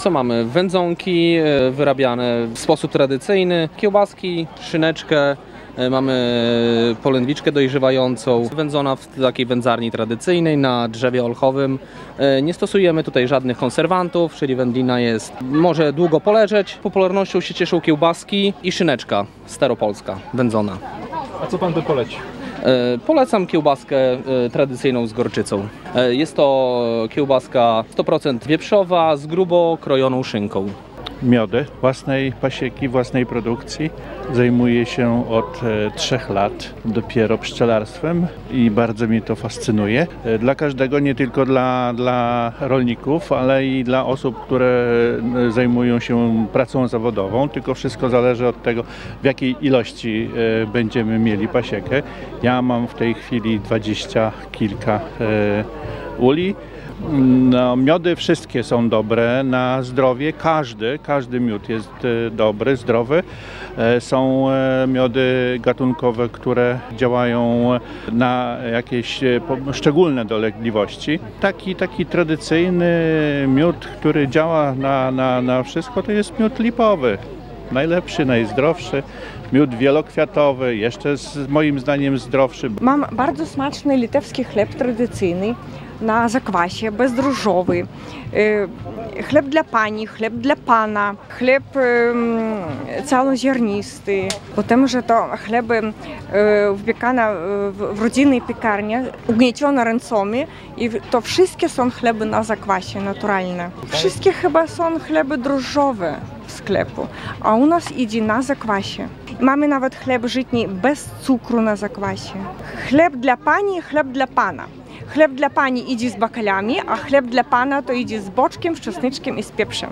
Jarmark Wigierski odbył się w środę (15.08.18) w pokamedulskim klasztorze w Wigrach.
W święto Wniebowzięcia Najświętszej Maryi Panny na odpust do wigierskiej parafii przybywają tysiące wiernych oraz dziesiątki kupców z ludowym rękodziełem, artystycznym rzemiosłem i regionalnymi potrawami. W przyklasztornych ogrodach przez cały dzień trwa wielka kupiecka wrzawa.